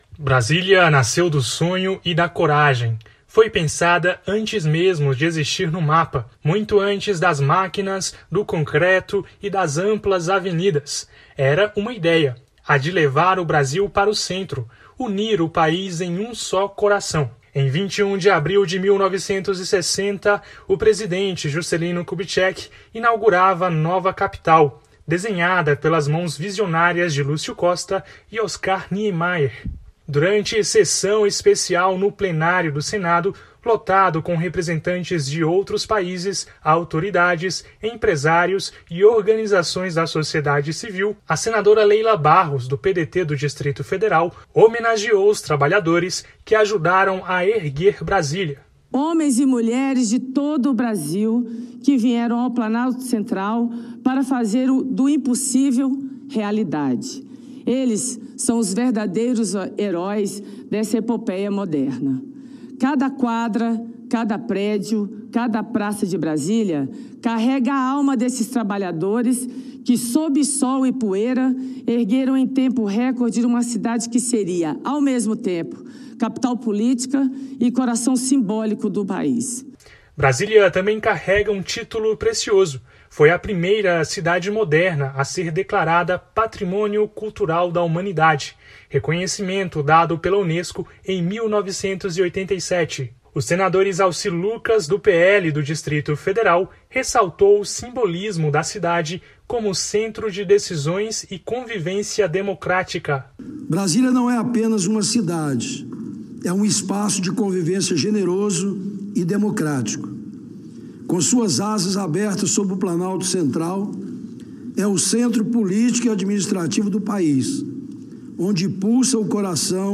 O Senado Federal comemorou os 65 anos de Brasília em uma sessão especial nesta segunda-feira (28). Idealizada por Juscelino Kubitschek e projetada por Lúcio Costa e Oscar Niemeyer, a capital federal foi lembrada como símbolo de modernidade e diversidade.